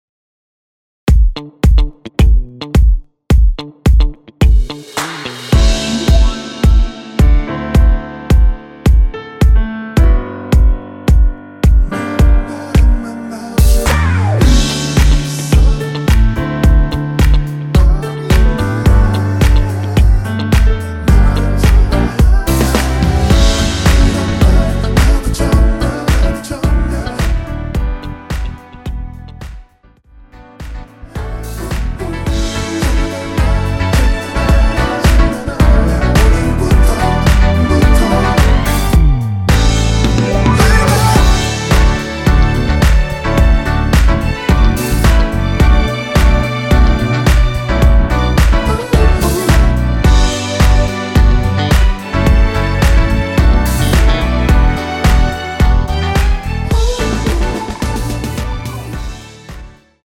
원키에서(-2)내린 코러스 포함된 MR입니다.(미리듣기 확인)
앞부분30초, 뒷부분30초씩 편집해서 올려 드리고 있습니다.